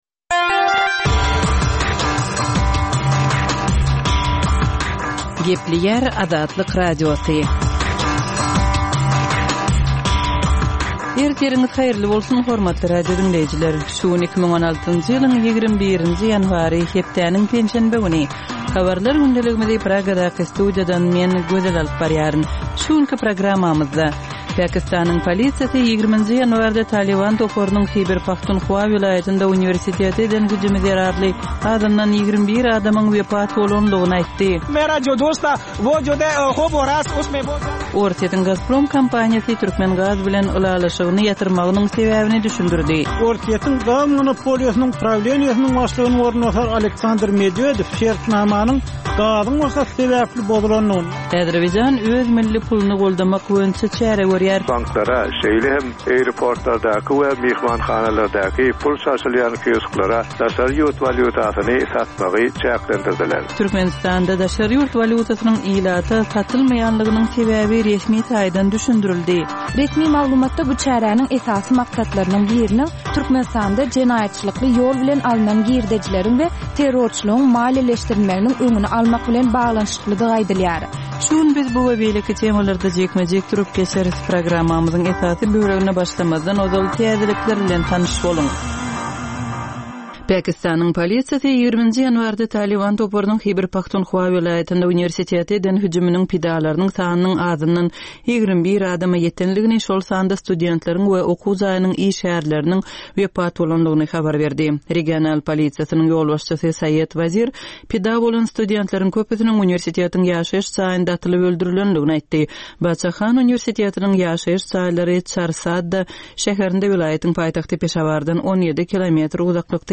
Dünýäniň dürli regionlarynda we Türkmenistanda şu günki bolan we bolup duran soňky wakalar barada gysgaça habarlar.